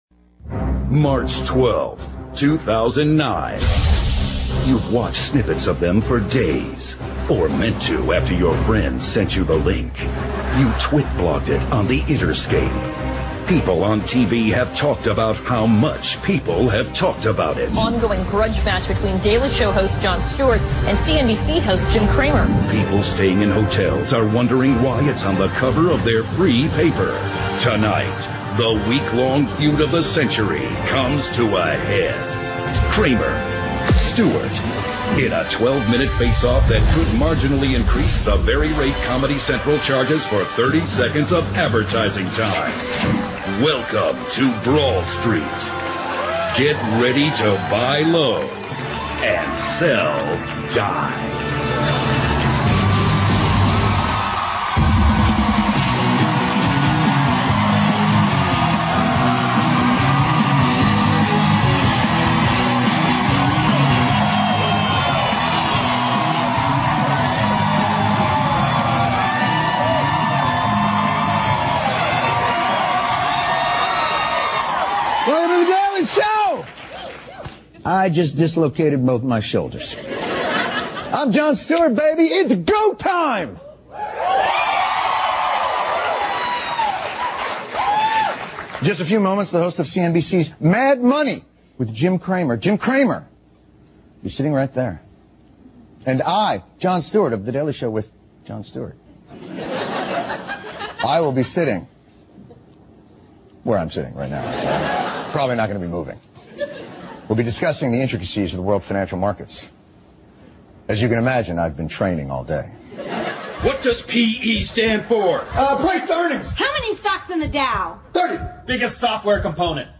访谈录 2009-04-25&04-27Jon Stewart vs Jim Cramer金融危机 听力文件下载—在线英语听力室